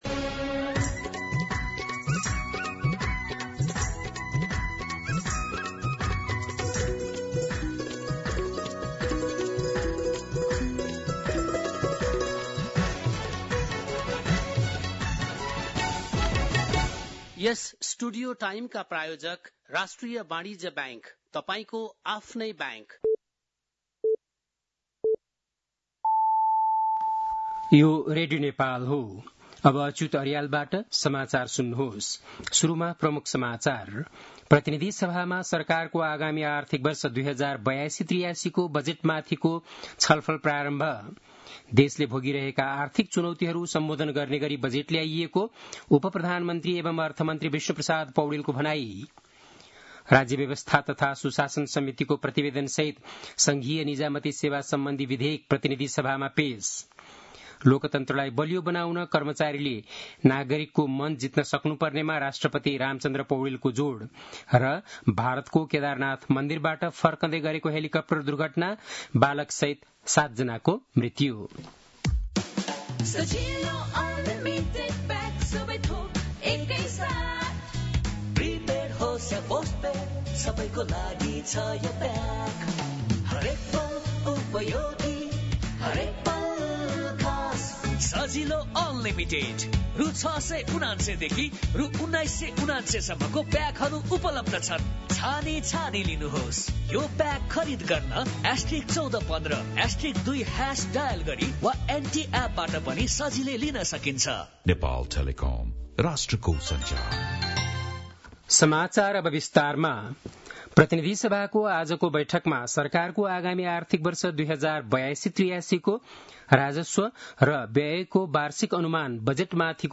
बेलुकी ७ बजेको नेपाली समाचार : १ असार , २०८२
7.-pm-nepali-news-1-2.mp3